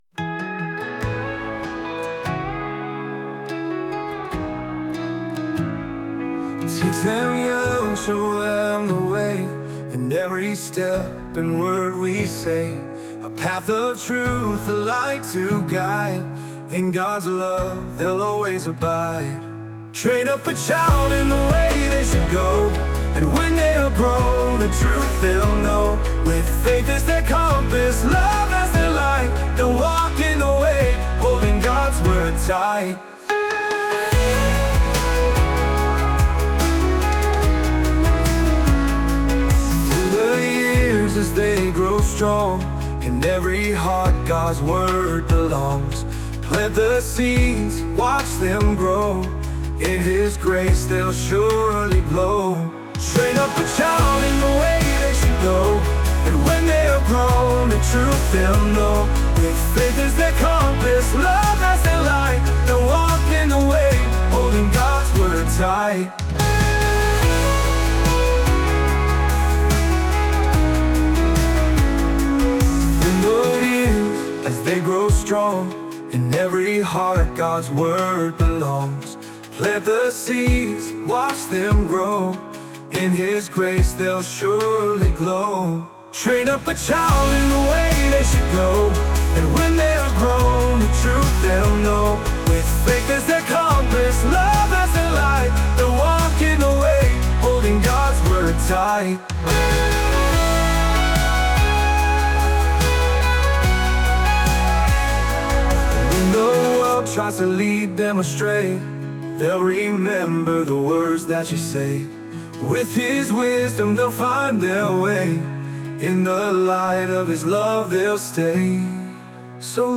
Christian Worship